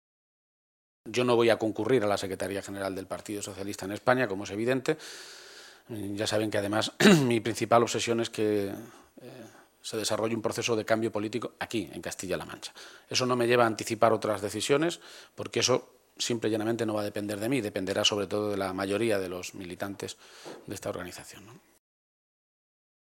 García-Page se pronunciaba de esta manera esta mañana en Toledo, a preguntas de los medios de comunicación.
Cortes de audio de la rueda de prensa